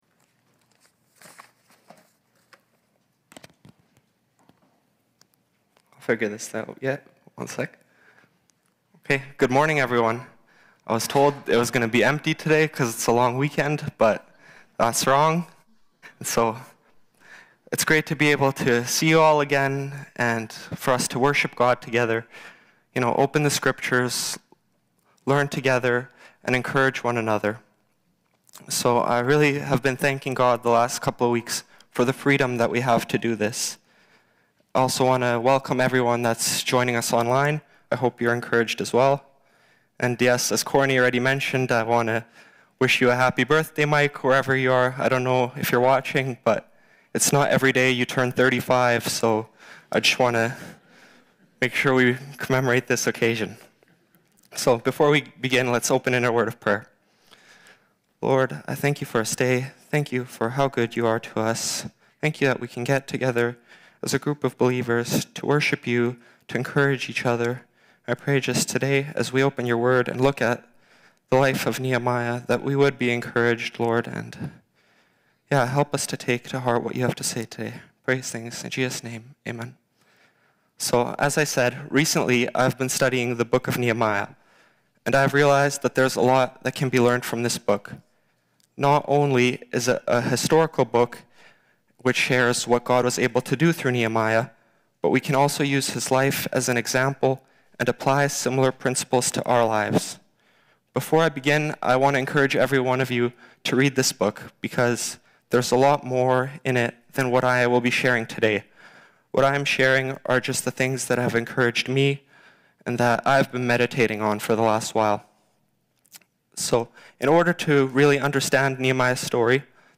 Nehemiah 1:1-9 Service Type: Sunday Morning « Communion I’ve Learned To Be Content